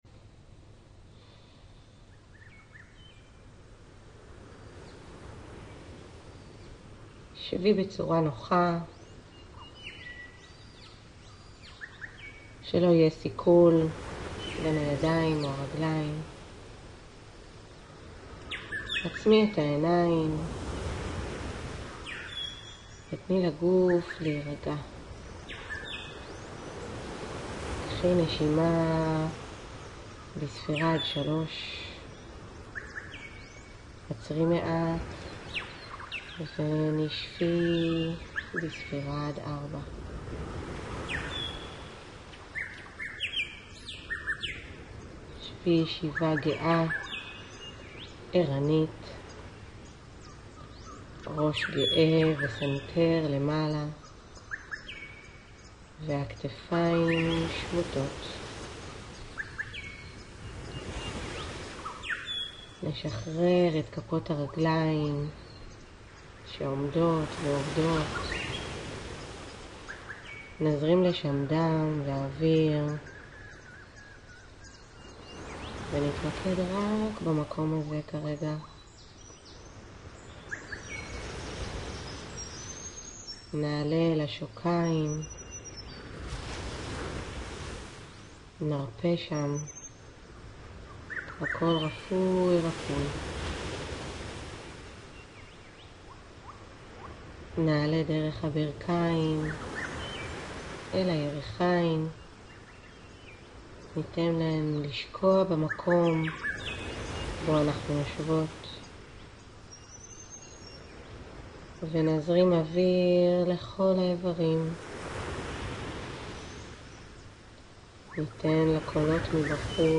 נוכחות עם מוסיקה : 8.5 דק
מדיטציית+הרפייה+1.mp3